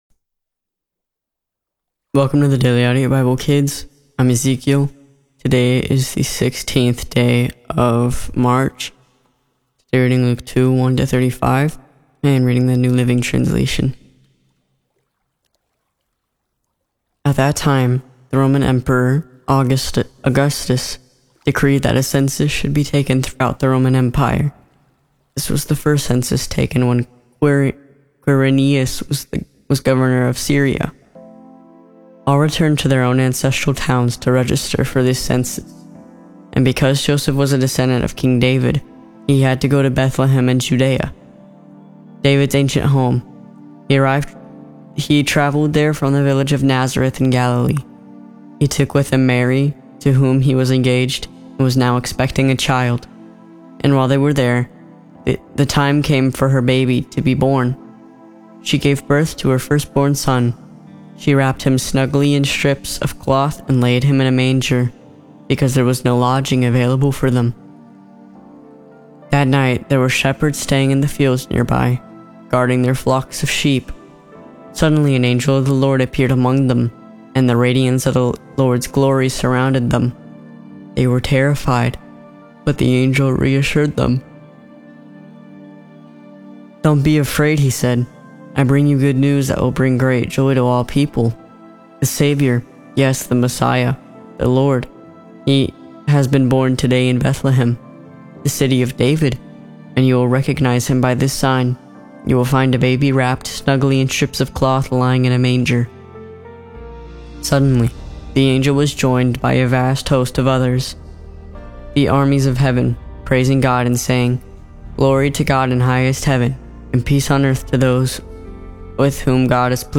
Through the Bible for kids by kids.